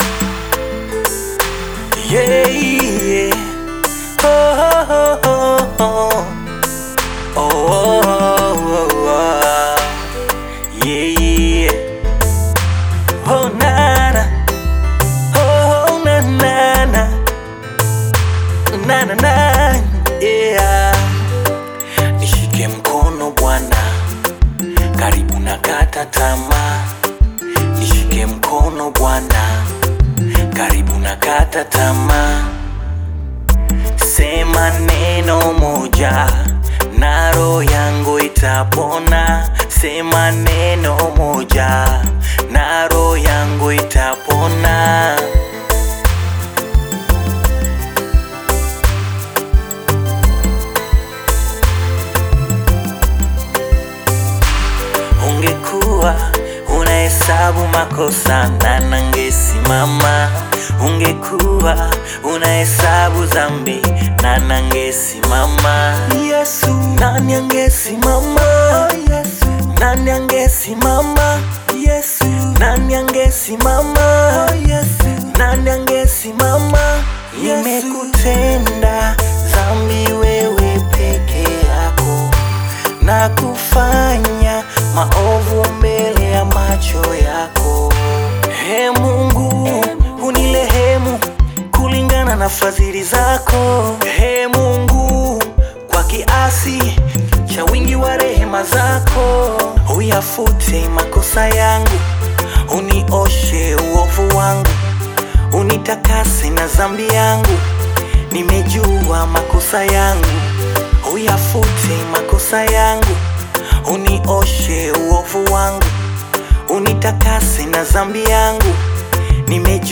AudioGospel